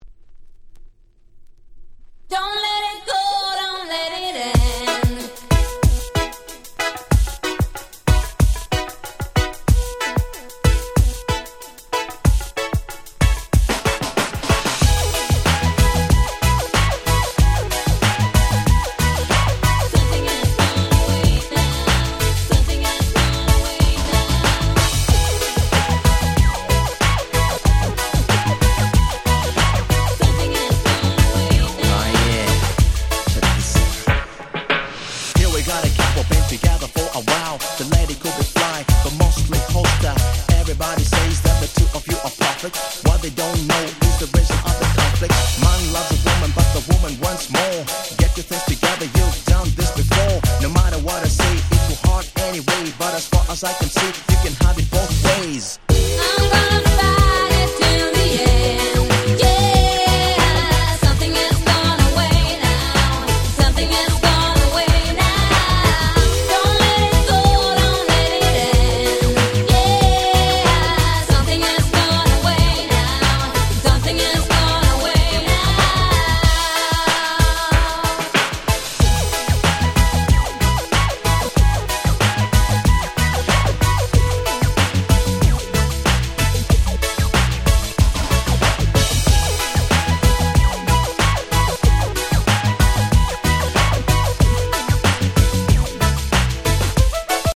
この辺のRagga Pop物、キャッチーでやっぱり最高ですよね。